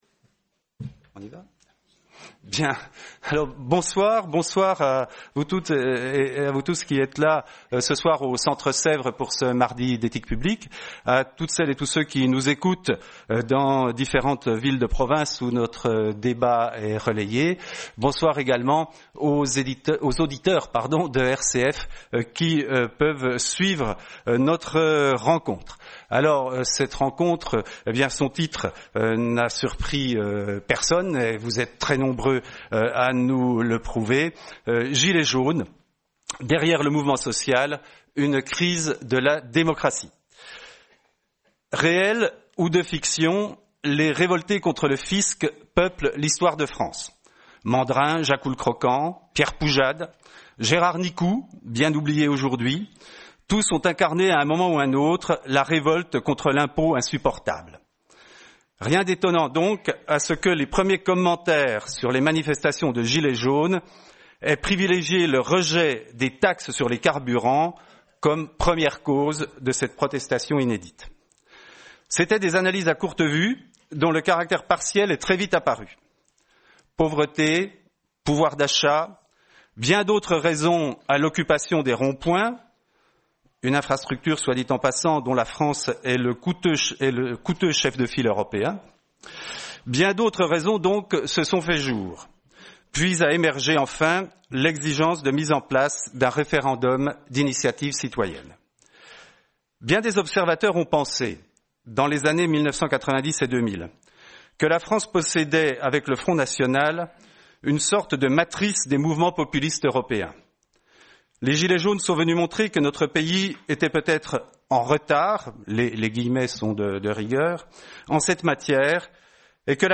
Soirée Mardi d’Ethique publique du 15 janvier 2019.